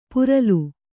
♪ puralu